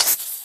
1.21.4 / assets / minecraft / sounds / mob / creeper / say1.ogg